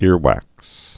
(îrwăks)